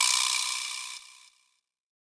Vibraslap Zion.wav